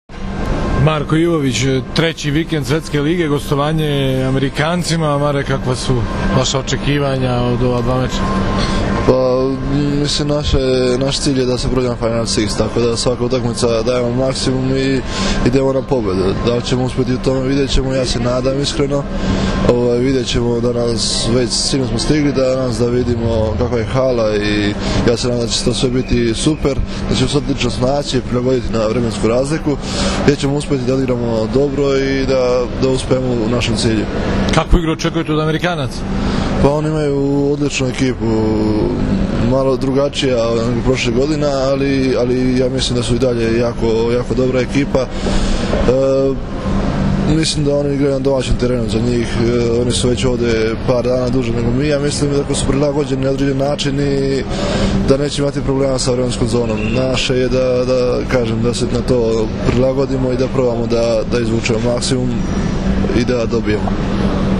IZJAVA MARKA IVOVIĆA